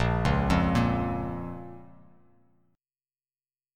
Bbm Chord
Listen to Bbm strummed